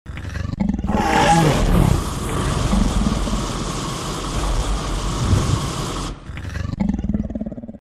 dragon the fire breath.mp4.mp3